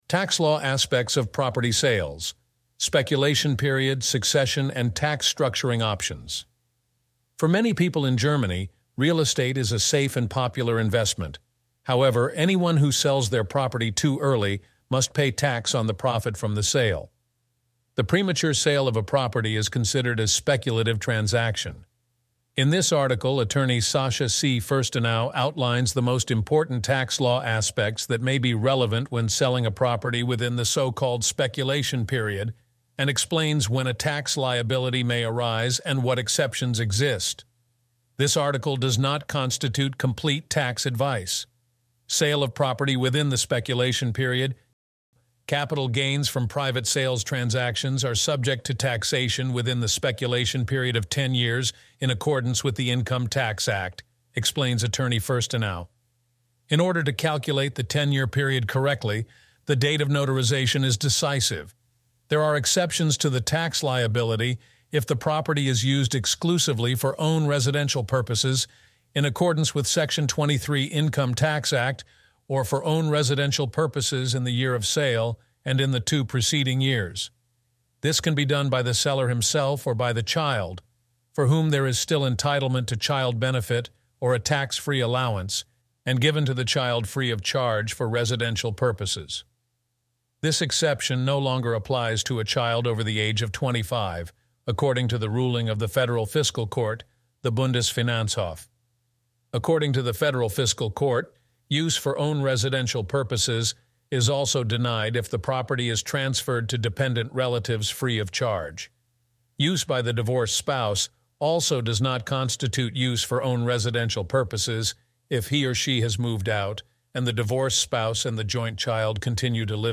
Read out the article